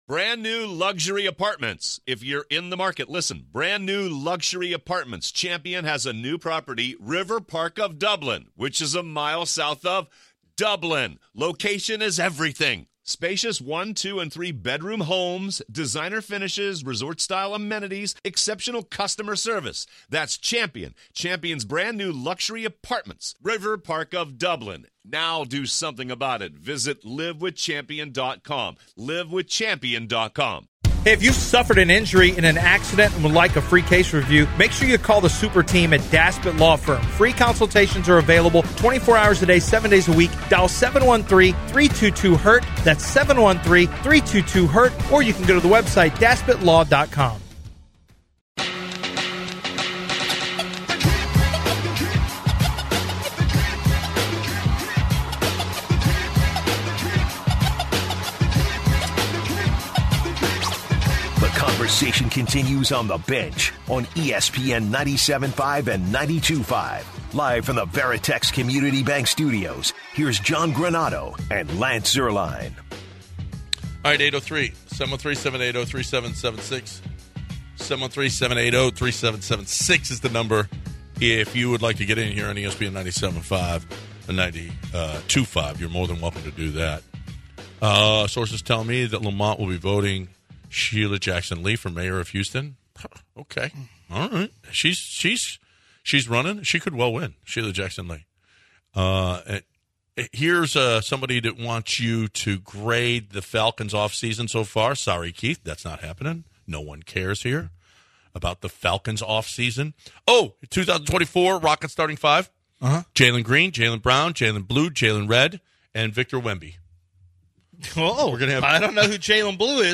In our second hour, we look at the possibilities for the Houston Texans in the NFL Draft. Also, We hear a very amazing freestyle from Harry Mack on the NBA on TNT. In addition, we learn what can happen if you leave someone off of the schedule at work.